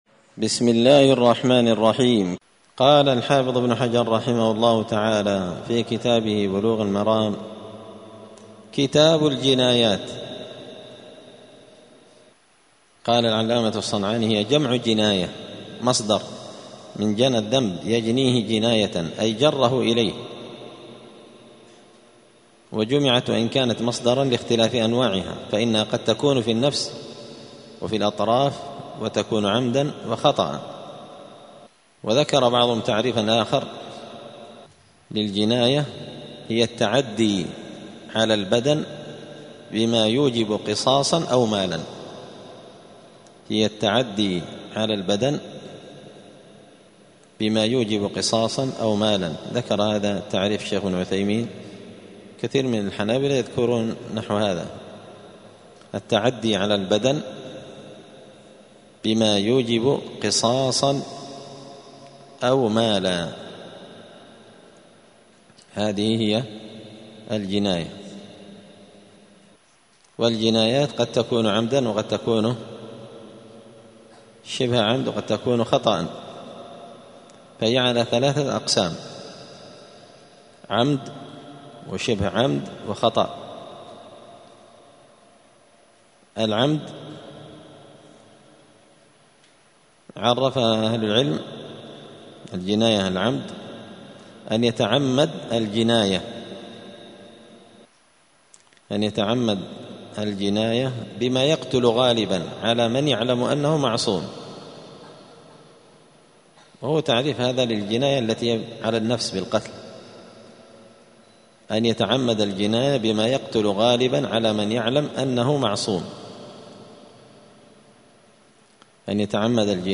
*الدرس الأول (1) {تعريف الجنايات}*